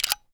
Reloading0003.ogg